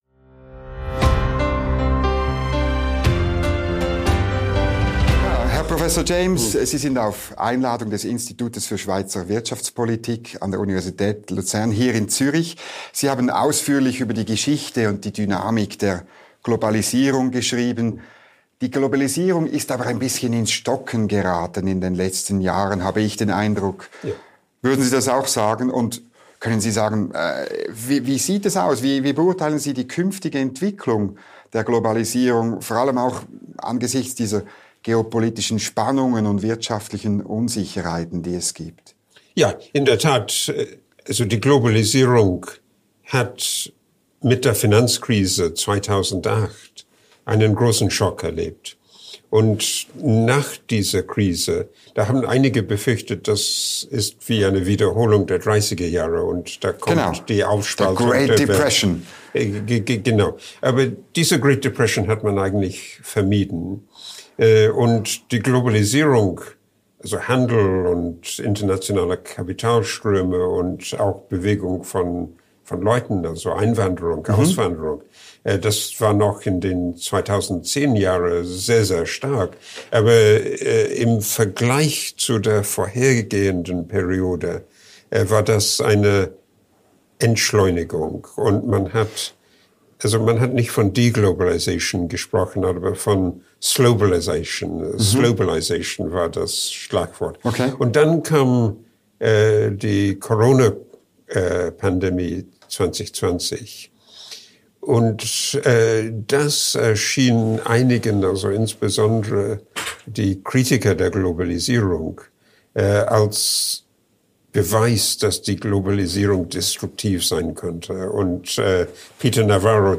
Im Interview redet er über die Globalisierung, die europäische Integration und den russischen Präsidenten Wladimir Putin und dessen Krieg gegen die Ukraine.